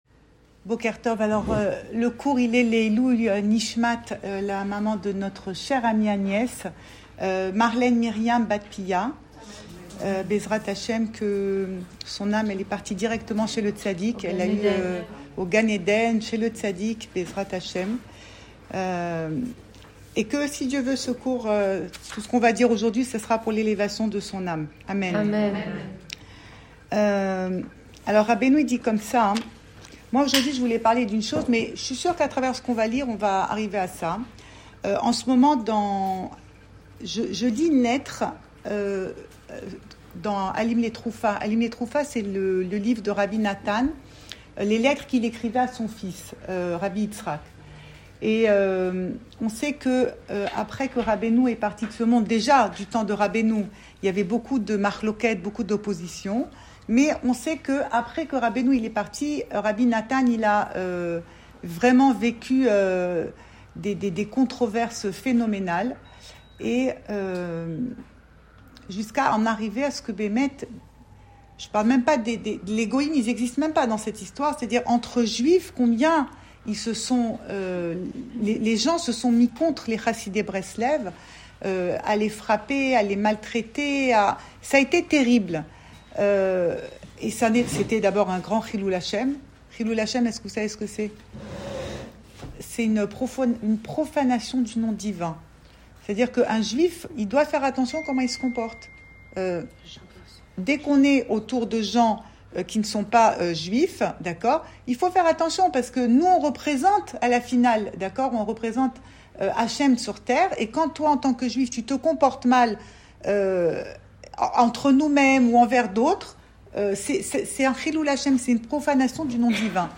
Cours audio Le coin des femmes Pensée Breslev - 12 juillet 2023 13 juillet 2023 Les 3 semaines : Qu’est ce qu’attend Hachem ? Enregistré à Tel Aviv